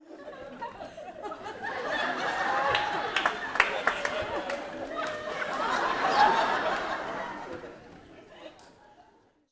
Audience Laughing-01.wav